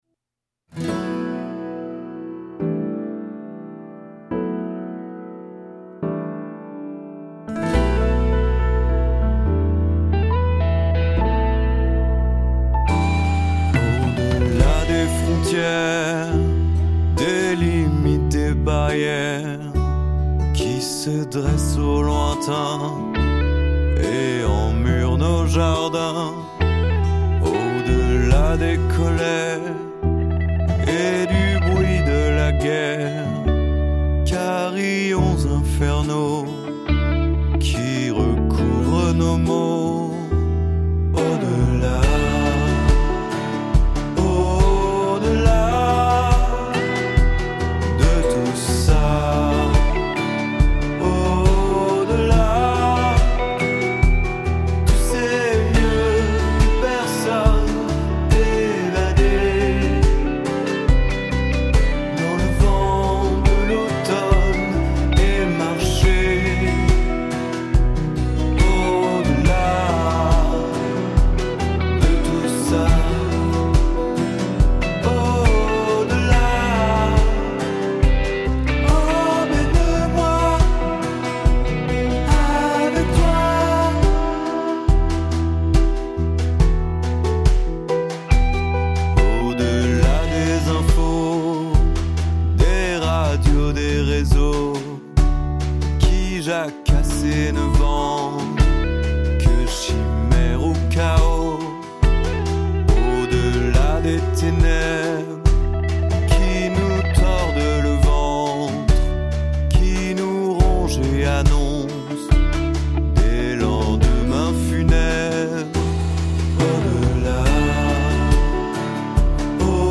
Télécharger la maquette MP3